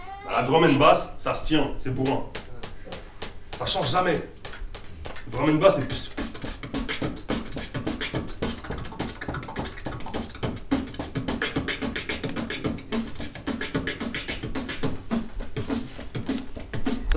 La classe d'écriture du Conservatoire de Rennes
Quelques moments, mal-filmés, avec un son pourri :
Drum'n Bass (
DrumNBass.wav